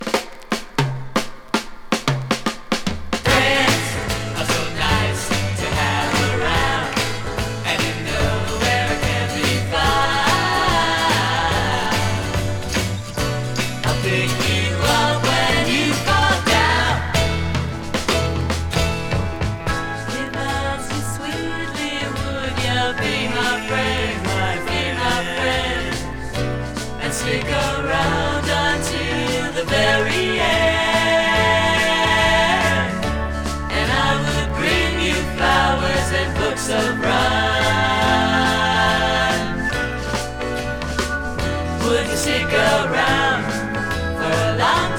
Rock, Pop, Soft Rock, Vocal　USA　12inchレコード　33rpm　Stereo